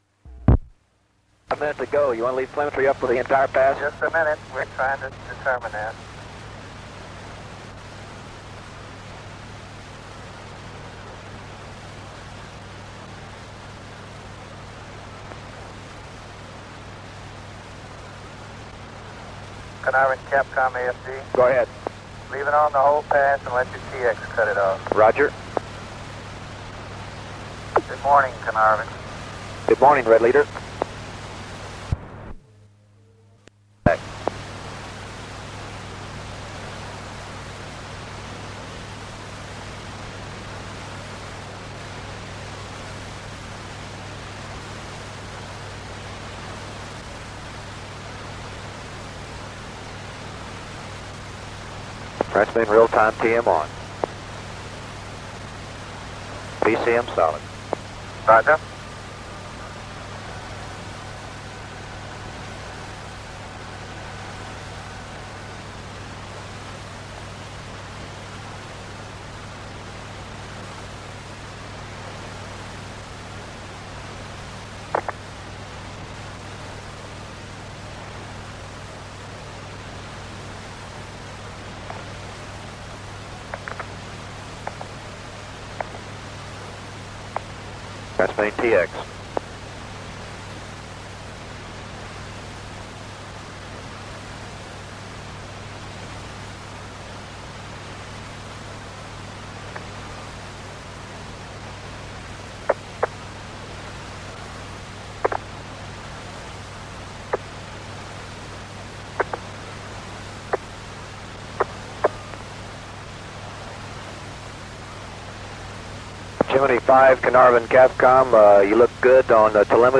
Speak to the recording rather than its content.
Gemini V audio – recorded at Carnarvon The audio files of each pass are unaltered, however periods of silence between passes have been removed or reduced.